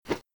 flap.ogg